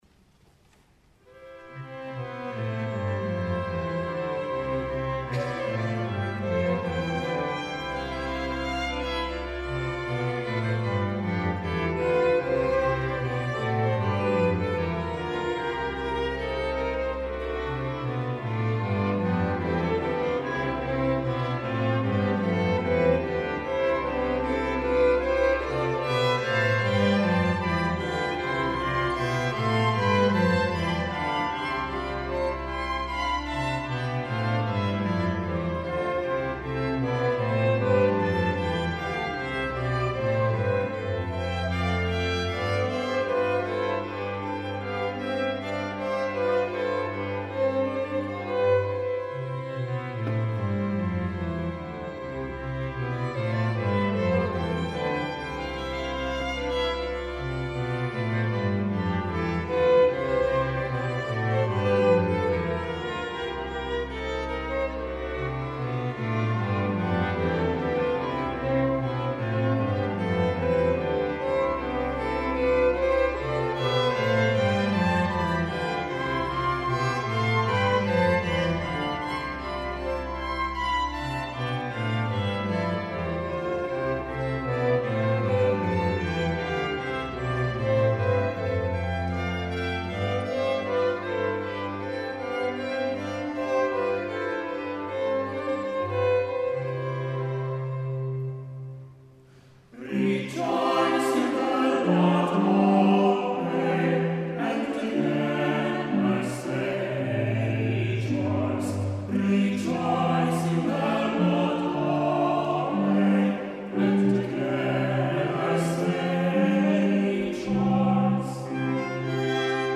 Klang - Chor Vokalensemble Capella Moguntina, Mainz